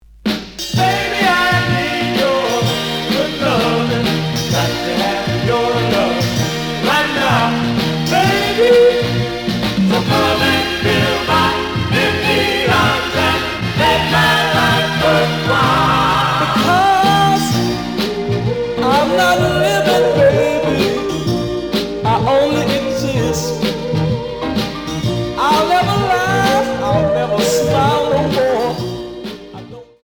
試聴は実際のレコードから録音しています。
●Genre: Soul, 70's Soul